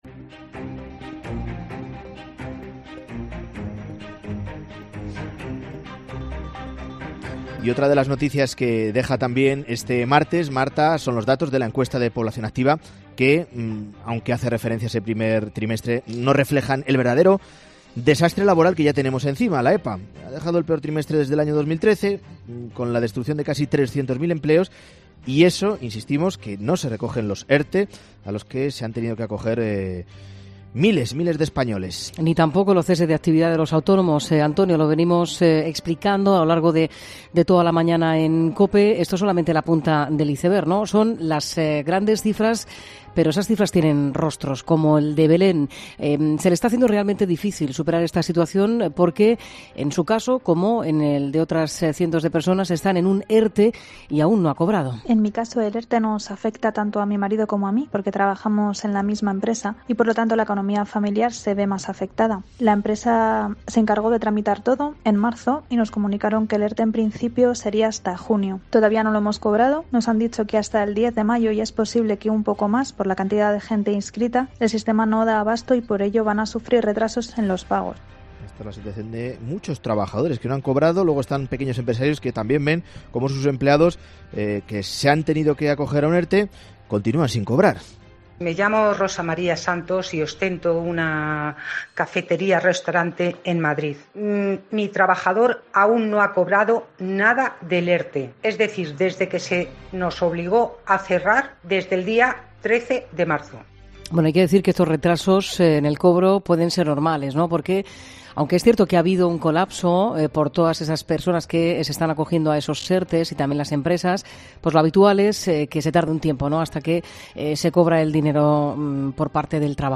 Un abogado laboralista nos responde a las preguntas que se hacen los afectados. Qué ocurre cuando alguien ha pasado el coronavirus y se incorpora a trabajar.